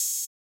AIR Open Hat.wav